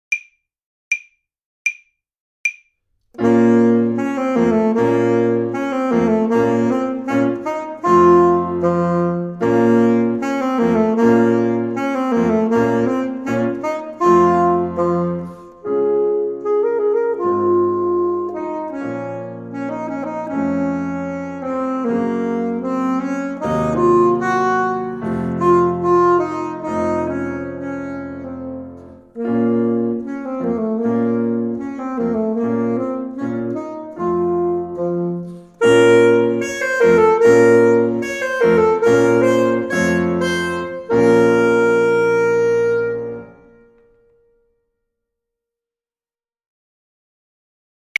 avec sax